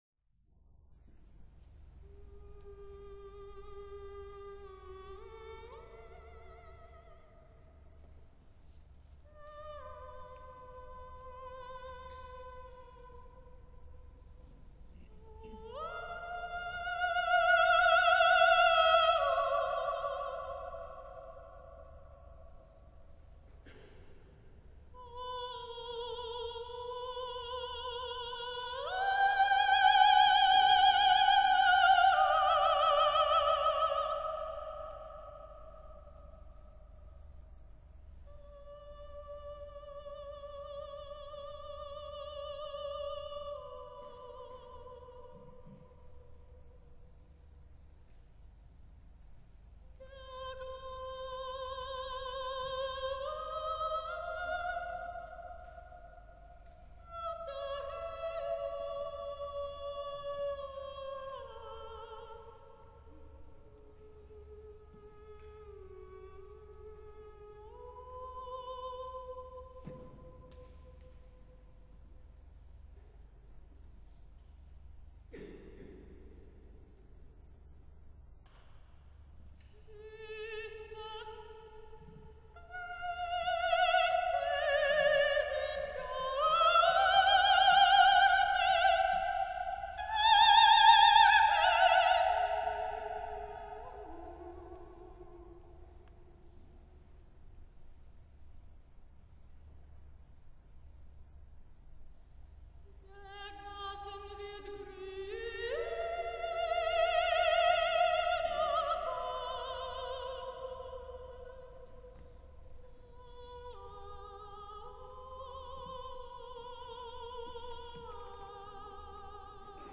Kantate für Sopran, Percussion und Orgel
UA 09/2016, Neuss